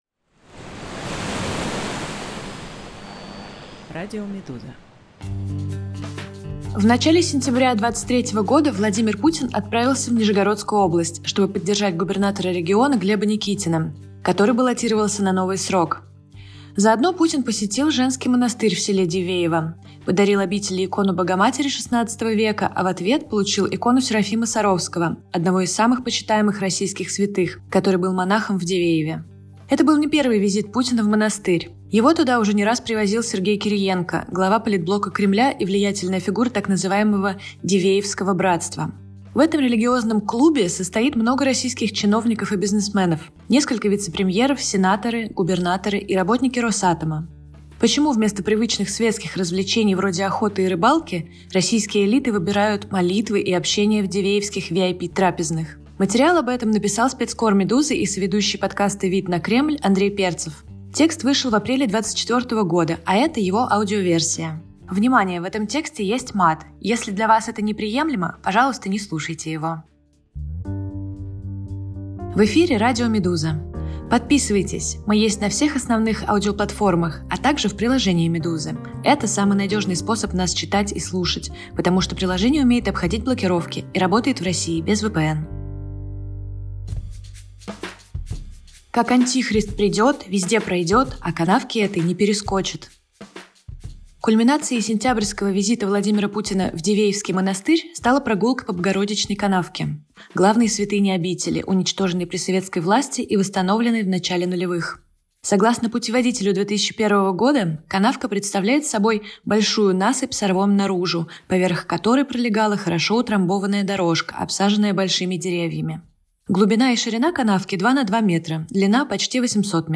Это аудиоверсия текста, а прочитать его можно здесь.